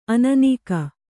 ♪ ananīka